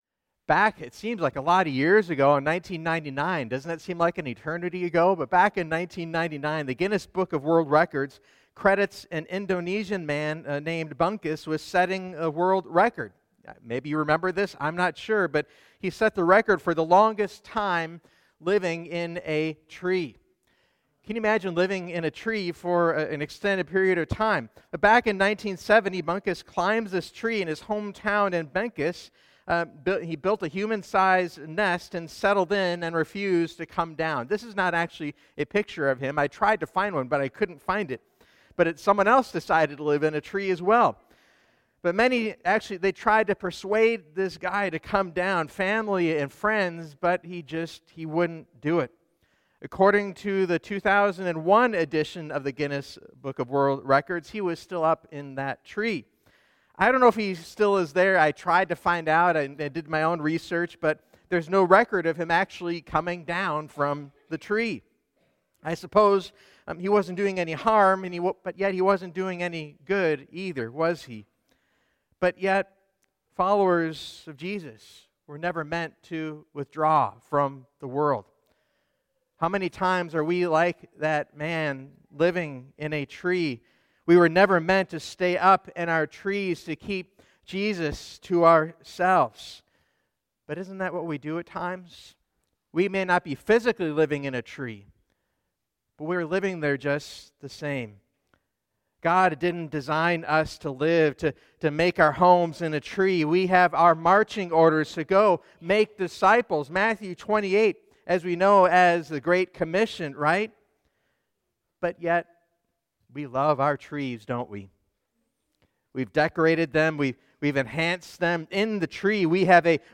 Here are the upcoming sermons in the series: “A New Beginning” “A Renewed Family” “A Renewed Mission”